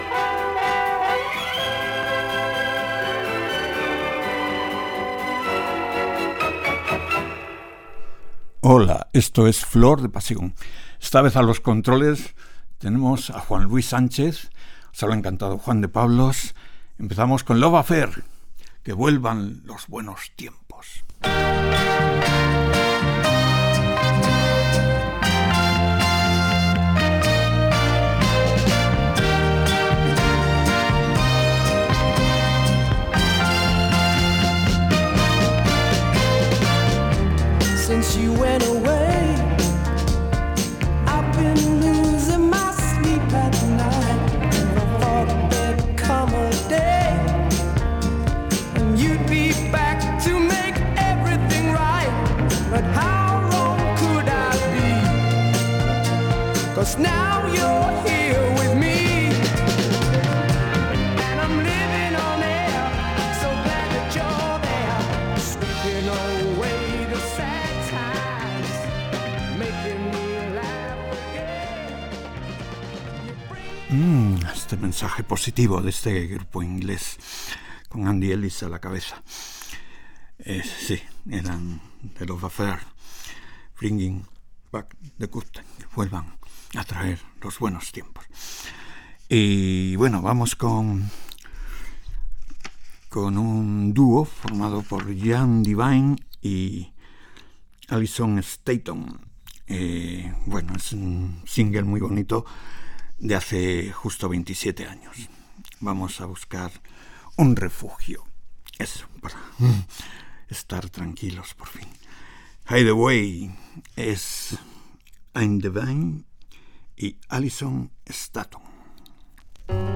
Sintonia, identificació del programa, equip, presentació de dos temes musicals
Musical